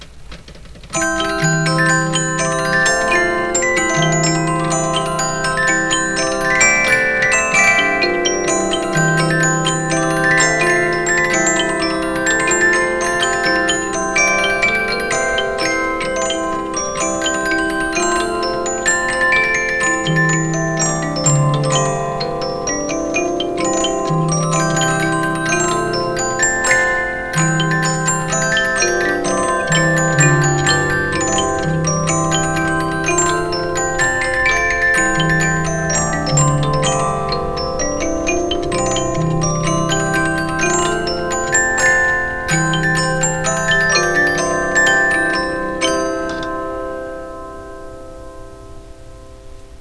Criterion Music Box
I have a Criterion Disc Music Box.